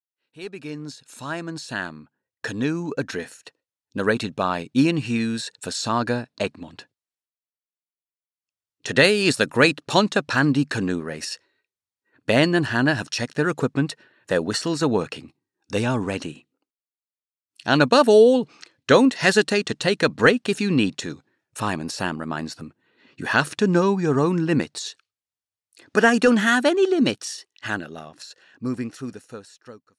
Audio knihaFireman Sam - Canoe Adrift (EN)
Ukázka z knihy